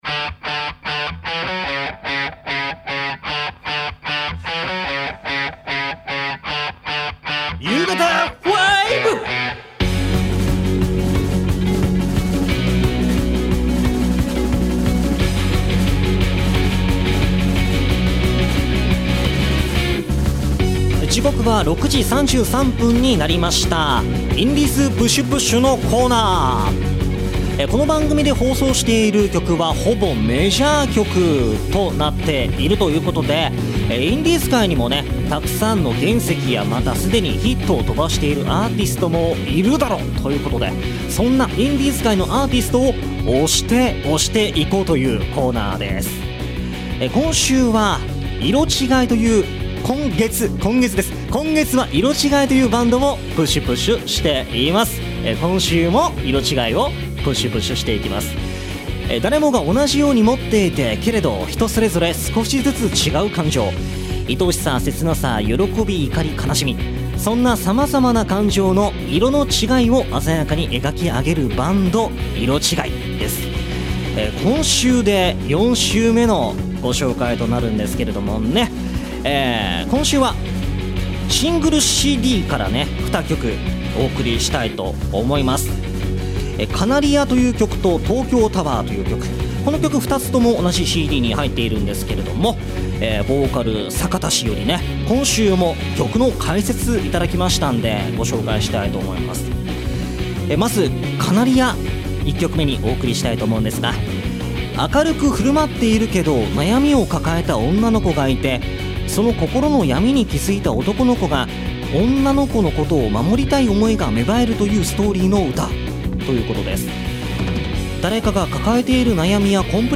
そんなキスの日に調布エフエムがエアコン工事中でして、空調なしのスタジオの中お送りしたゆうがた５…